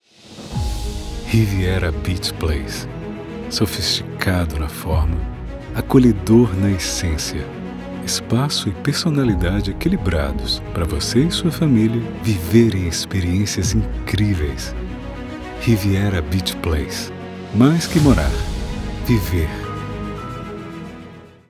Immobilier
Ma voix est naturelle et professionnelle. Elle est souvent décrite comme crédible, veloutée et douce, inspirant confiance et calme à l'auditeur.
Microphone : Neumann TLM103
Cabine vocale acoustiquement isolée et traitée
BarytonBasseProfondBas